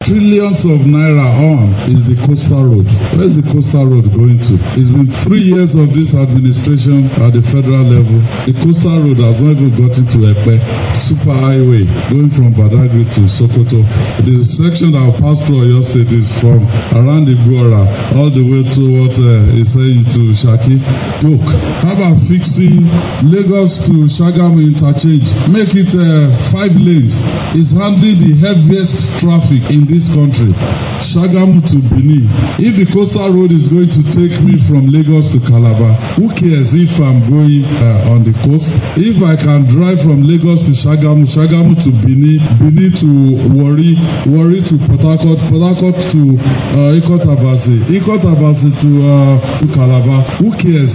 Listen to Governor’s reaction below’